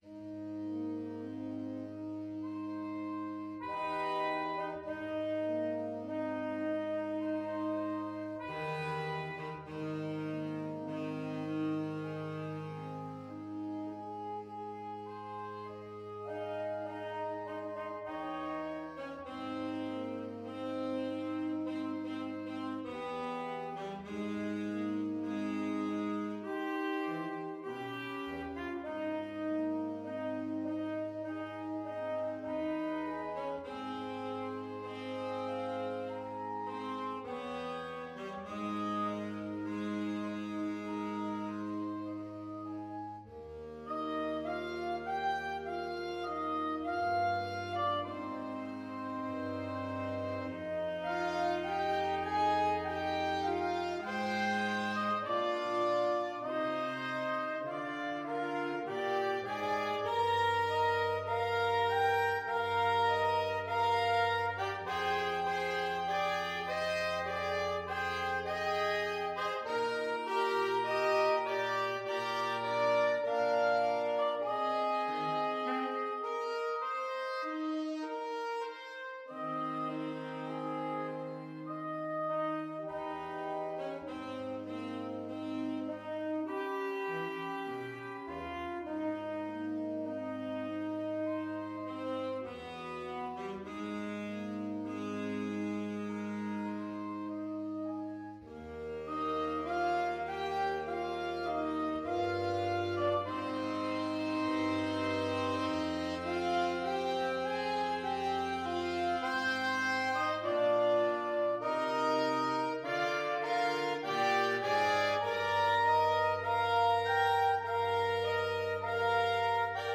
Free Sheet music for Saxophone Quartet
Soprano SaxophoneAlto SaxophoneTenor SaxophoneBaritone Saxophone
4/4 (View more 4/4 Music)
Eb major (Sounding Pitch) (View more Eb major Music for Saxophone Quartet )
~ = 50 Andante sostenuto
Saxophone Quartet  (View more Intermediate Saxophone Quartet Music)
Classical (View more Classical Saxophone Quartet Music)